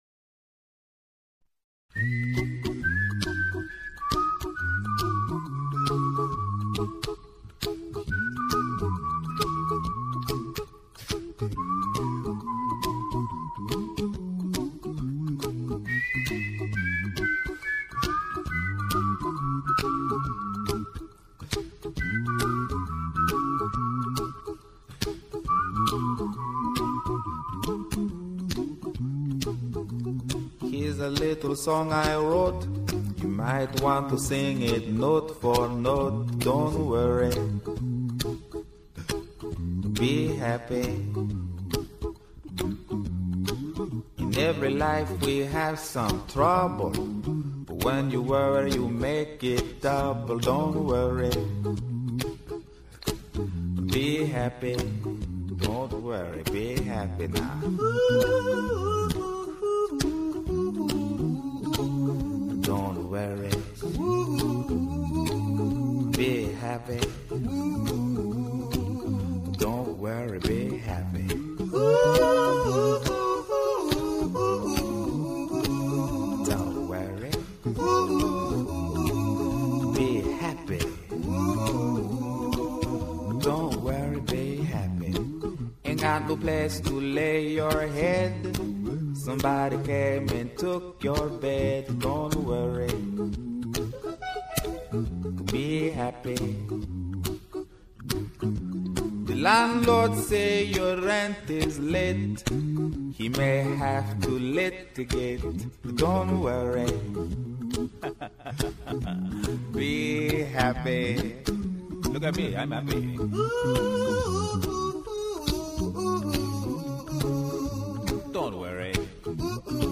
他是用口哨、响指、“人声模拟”小号及动感的嘟嘟呜呜声作为整首曲子的伴奏的
------到最后，竟分不清哪是歌唱、哪是伴奏，
不愧为a cappella首打曲目。